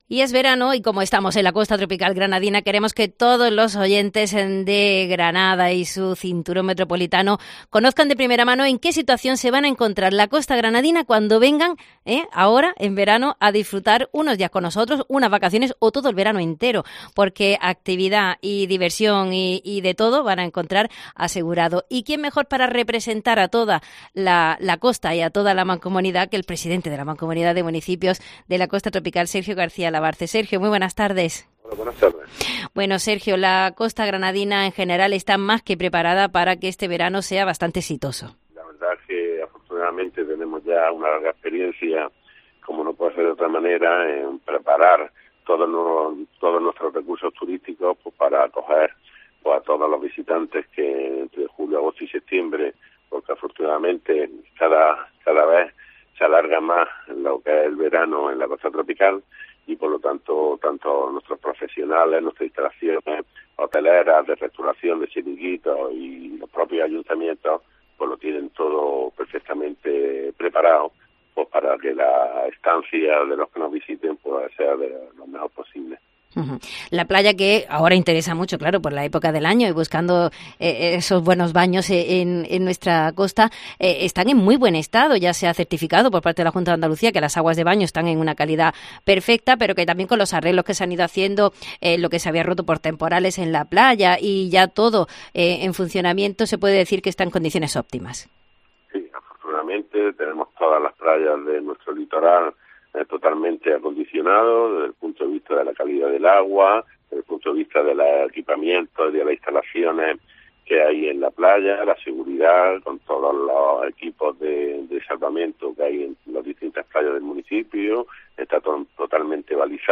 Hablamos con Sergio García Alabarce, presidente de la Mancomunidad de Municipios de la Costa Tropical, sobre cómo se colabora para que el verano en nuestra comarca sea rico y variado en actividades.